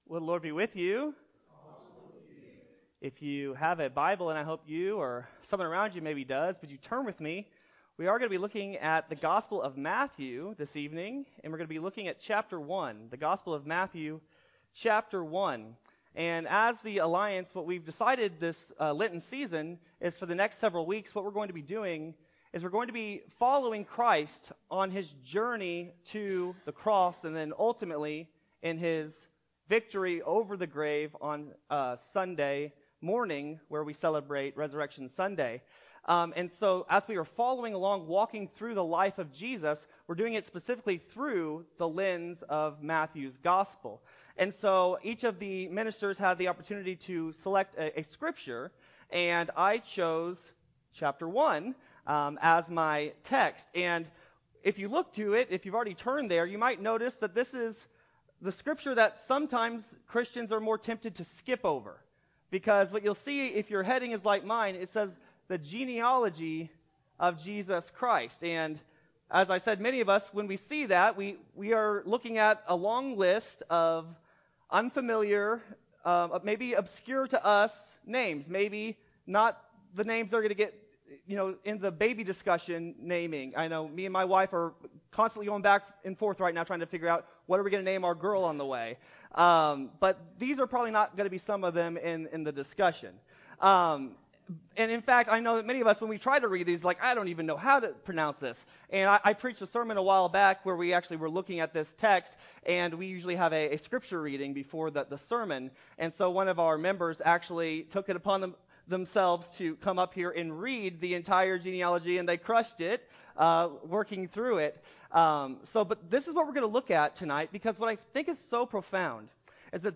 Carterville Ministerial Alliance Lenten Service: The Genealogy of Jesus Christ